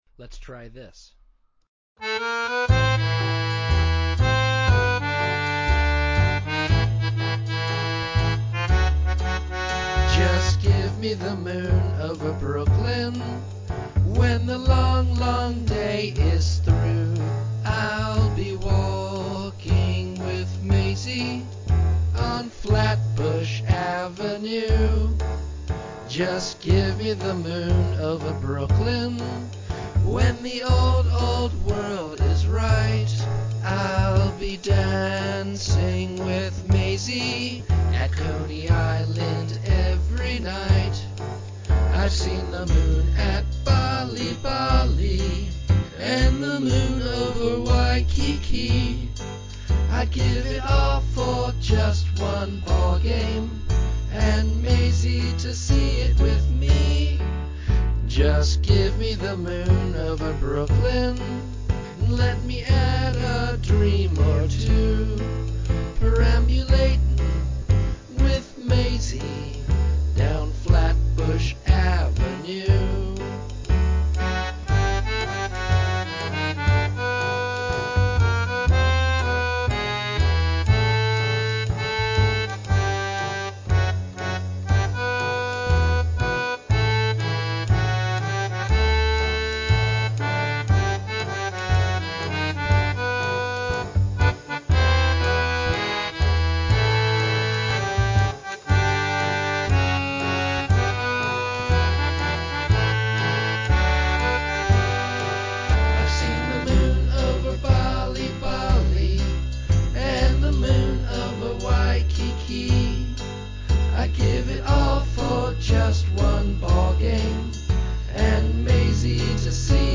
, solo voice & uke version with intro